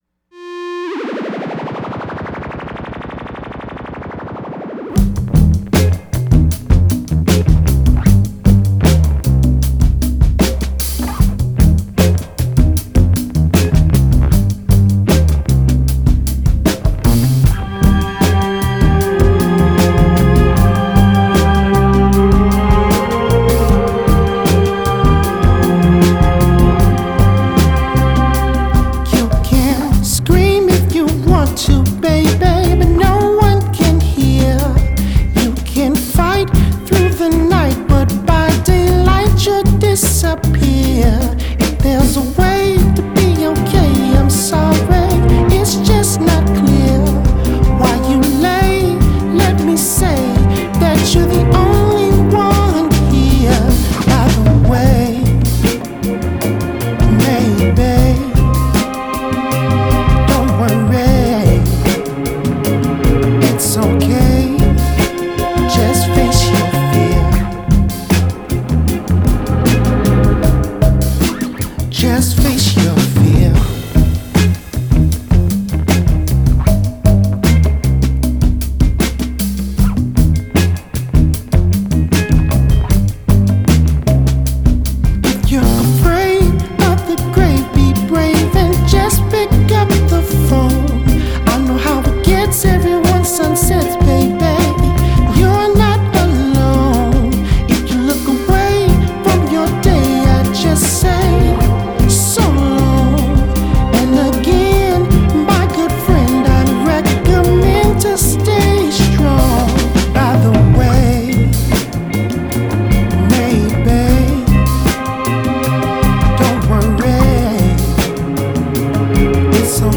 a falsetto-led minor key mini symphony.